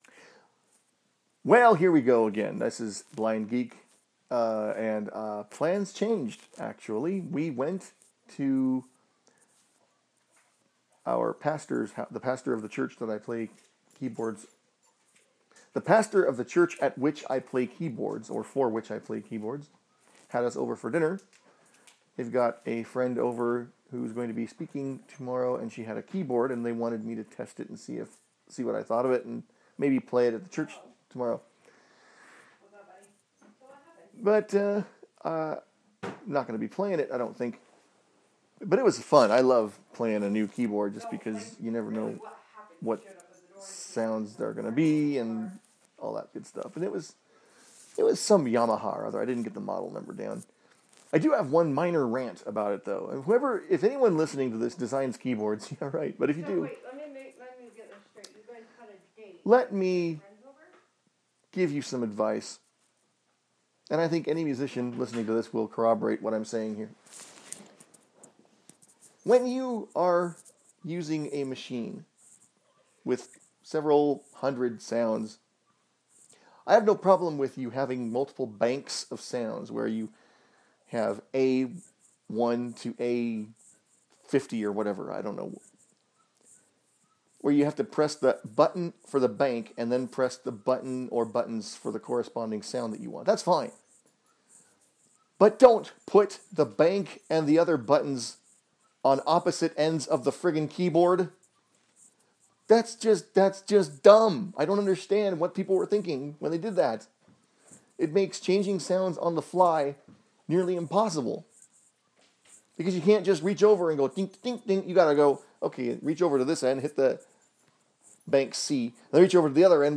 This is a boo about me testing a friend's music keyboard, and a resulting mini rant.